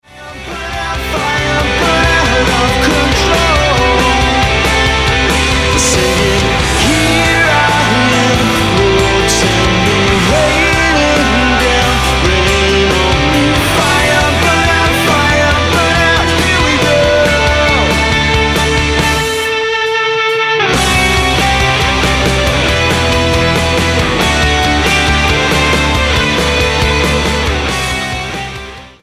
the four piece pop rock band
Rock Album